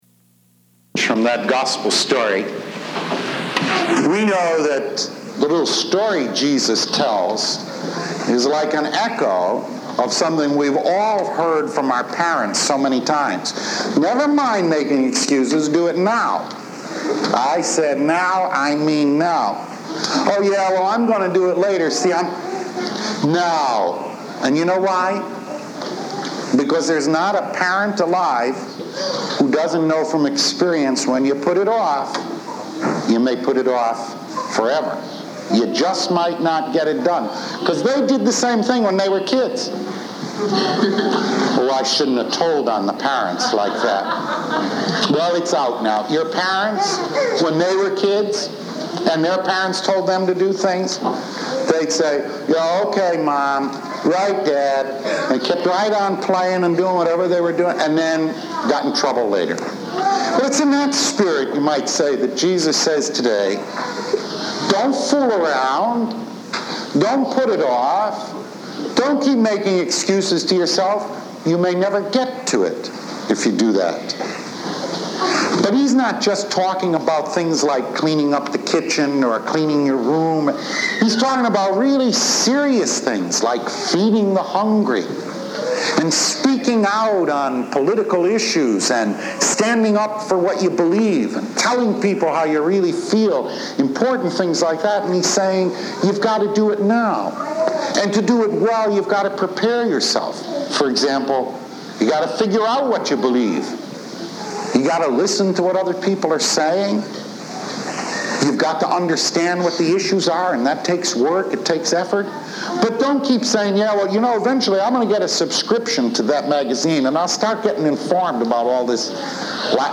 Readings: Wisdom 6:12-16; Thessalonians 4:13-17 or 4:13-14; Matthew 25: 1-13